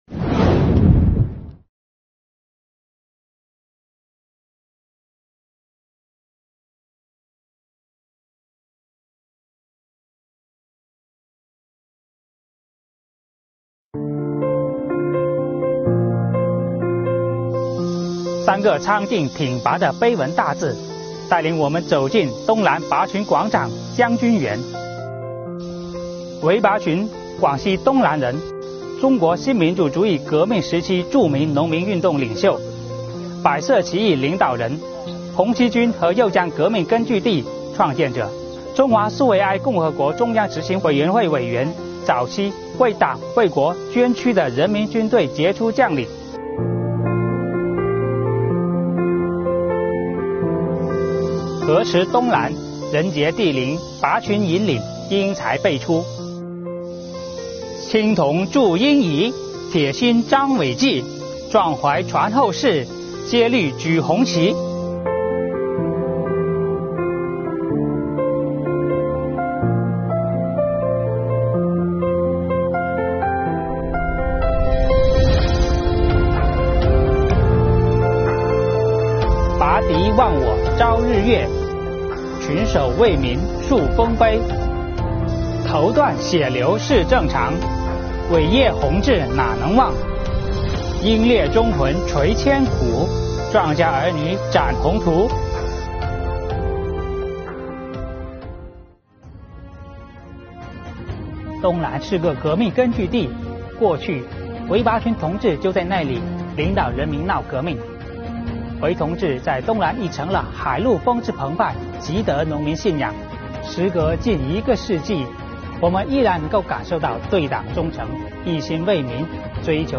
开展纪念碑碑文敬读活动，正是其中一项很有意义的活动。
百岁华诞、百年荣光，青年税务干部站在革命烈士纪念碑前致敬追思，巍巍巨柱纪录着红色土地上那段打土豪、除劣绅、兴农讲、救农民悲壮历史。